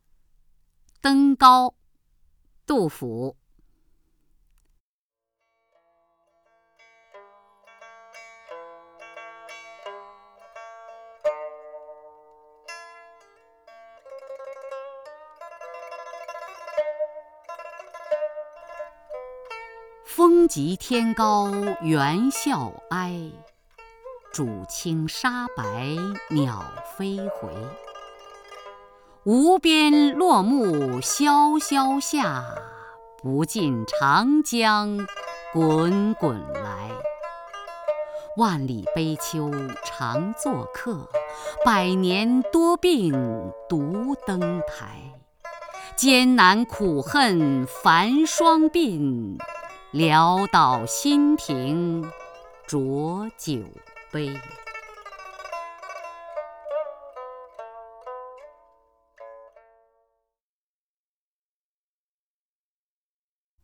雅坤朗诵：《登高》(（唐）杜甫) （唐）杜甫 名家朗诵欣赏雅坤 语文PLUS